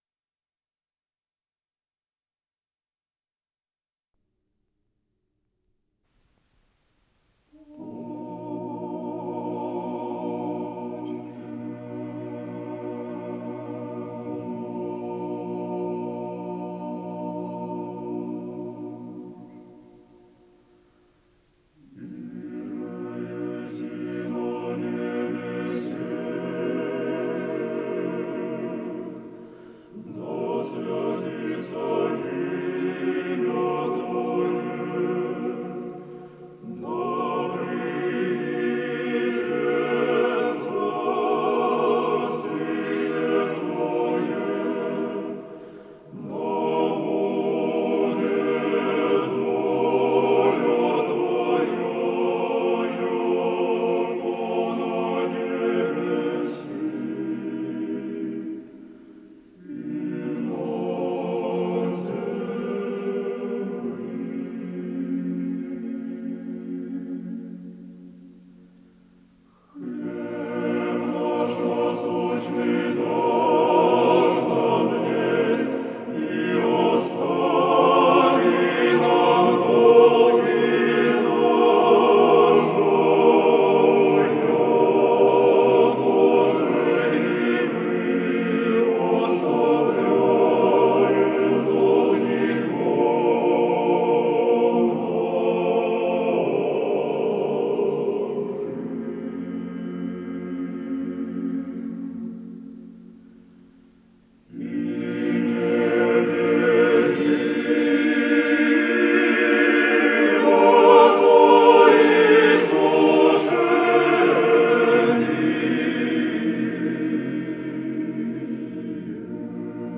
с профессиональной магнитной ленты
ИсполнителиМужской вокальный ансамбль
Скорость ленты38 см/с